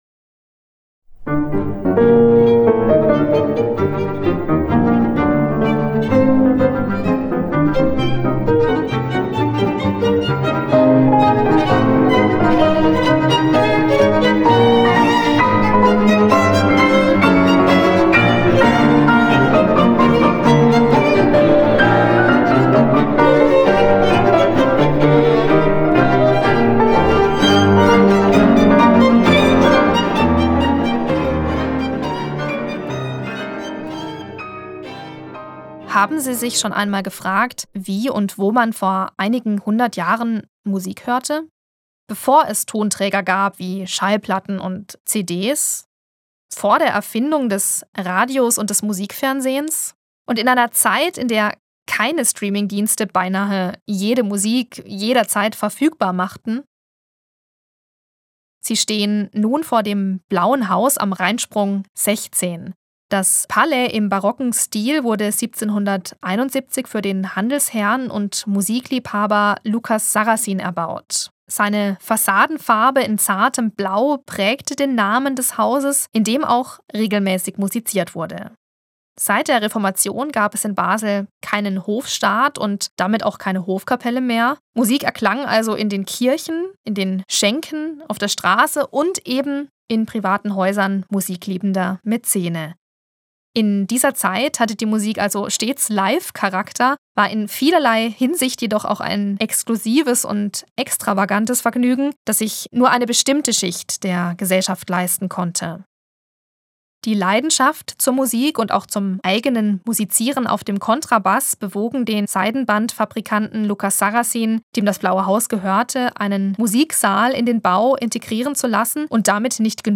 QUELLEN der Musikbeispiele: